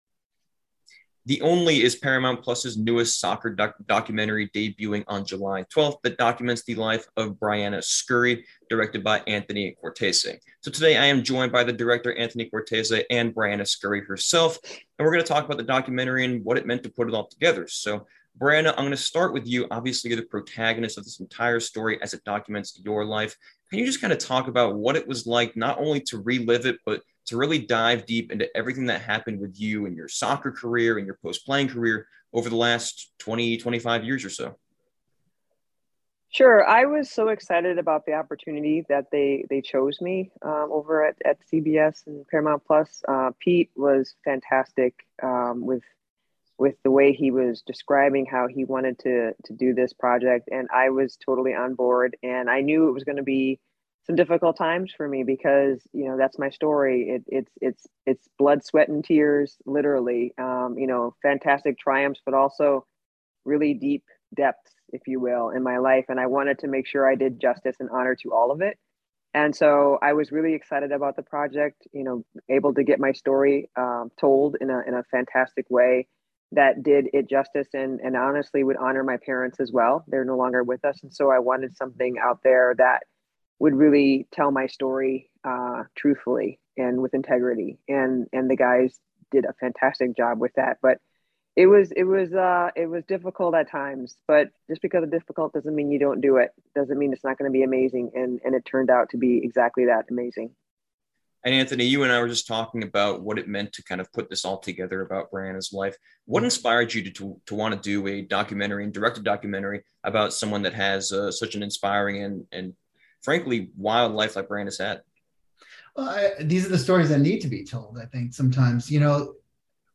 Briana Scurry interview